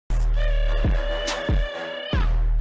Play, download and share Dondurma donate sesisisiss original sound button!!!!
skrrtt-sound-effect.mp3